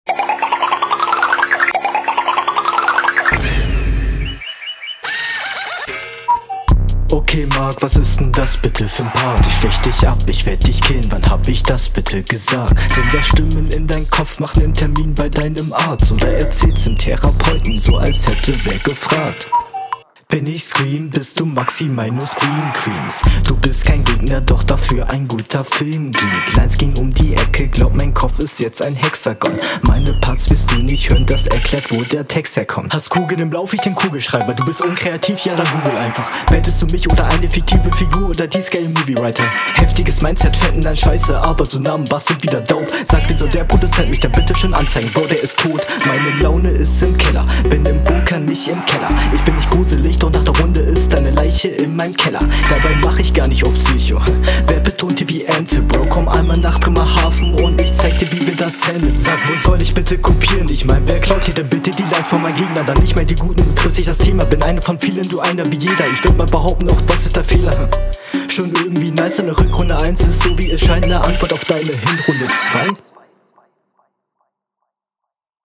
Coole Flows und Konter.
Flow klingt routiniert und langweilig...